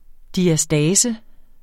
diastase substantiv, fælleskøn Bøjning -n Udtale [ diaˈsdæːsə ] Oprindelse fra græsk diastasis 'adskillelse' Betydninger 1.